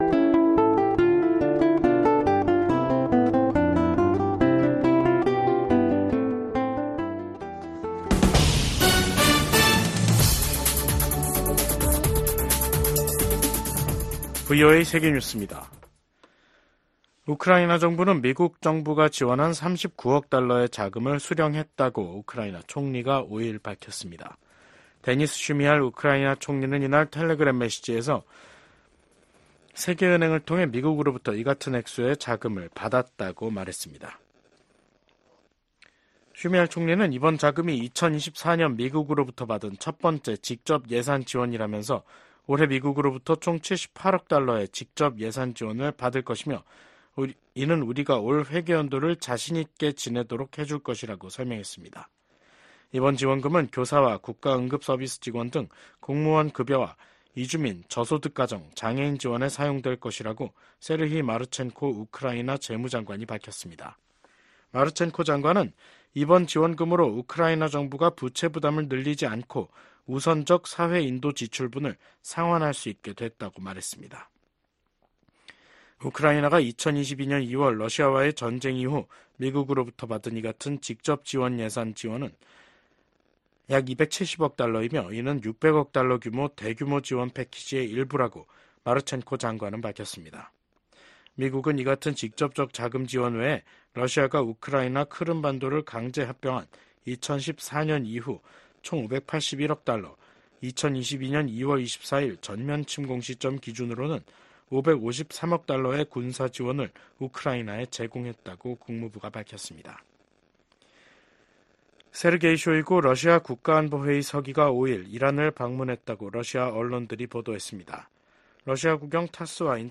VOA 한국어 간판 뉴스 프로그램 '뉴스 투데이', 2024년 8월 5일 3부 방송입니다. 북한이 핵탄두 장착이 가능한 신형 전술탄도미사일 발사대를 대규모 전방 배치한다고 발표했습니다. 한국 군 당국은 해당 무기체계 성능과 전력화 여부에 대해 추적 중이라고 밝혔습니다. 미국 국방부가 오는 11월 미국 대선을 전후한 북한의 7차 핵실험 가능성과 관련해 계속 주시할 것이라는 입장을 밝혔습니다.